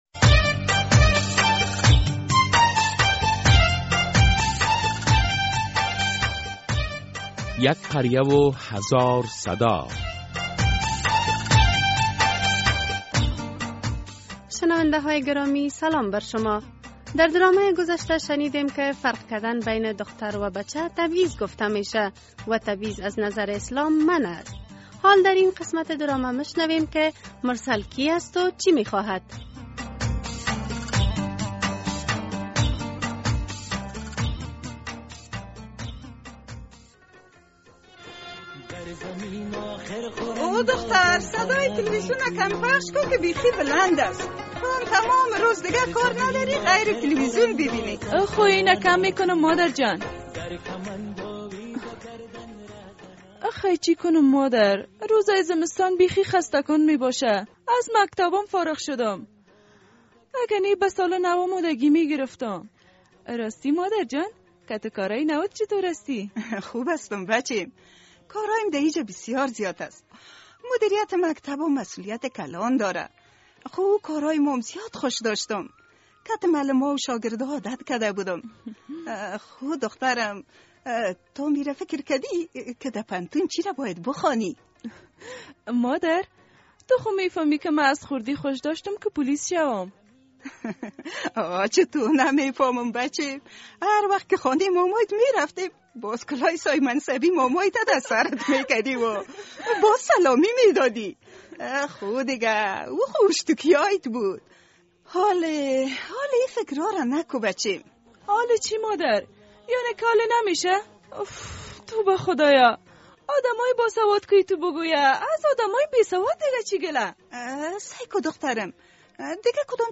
در قسمت ۱۸۳مین درامه یک قریه هزار صدا روی اهمیت تعلیم صحبت می‌شود. چرا برخی از خانواده‌ها به تعلیم و تحصیل دختران‌شان توجه نمی‌کنند؟